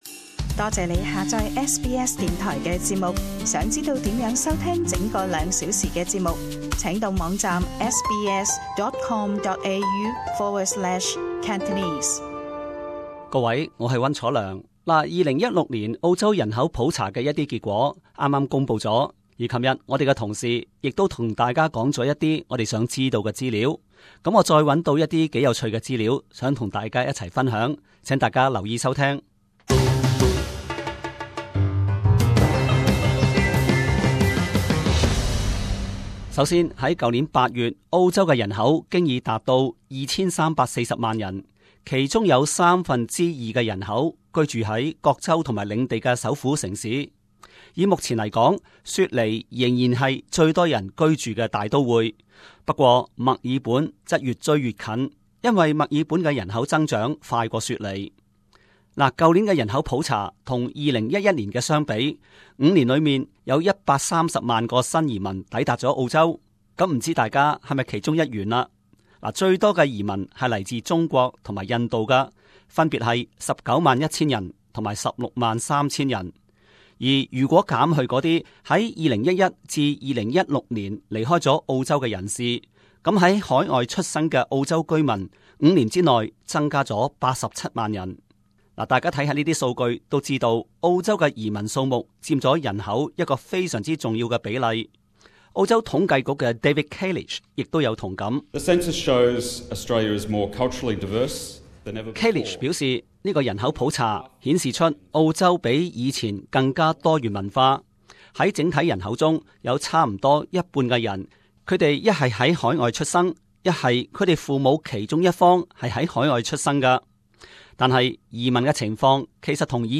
【時事報導】普通話遠遠抛離阿拉伯語和廣東話成澳洲第二大語言